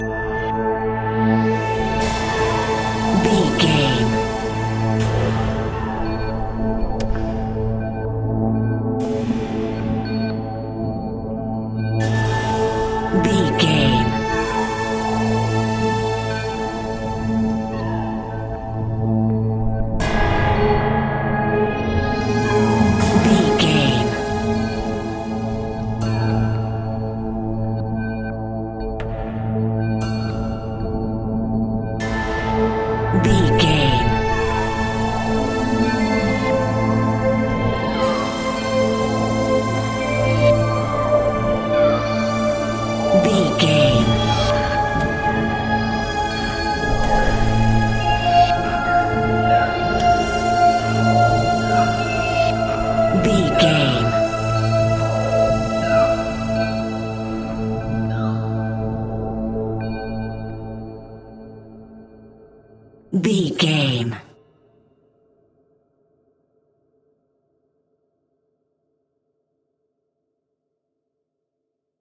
Aeolian/Minor
ominous
dark
suspense
eerie
piano
percussion
strings
synthesiser
atmospheres